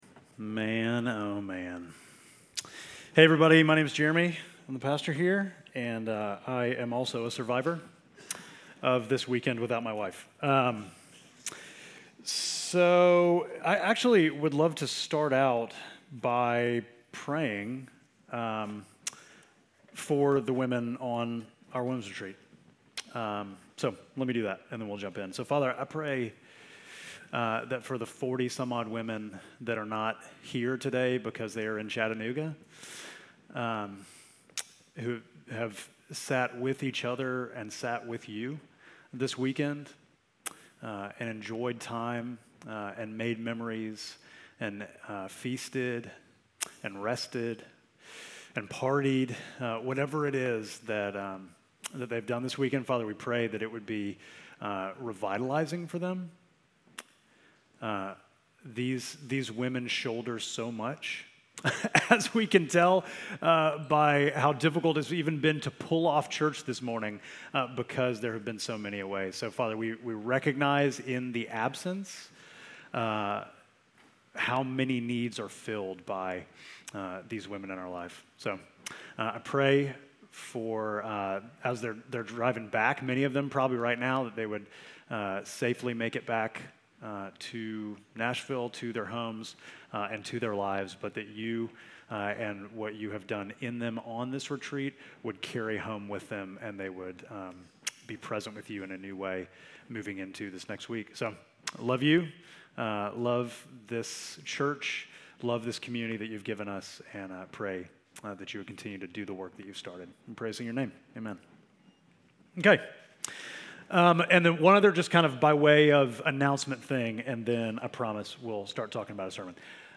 Midtown Fellowship Crieve Hall Sermons Am I Allowed to Enjoy Life?